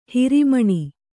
♪ hiri maṇi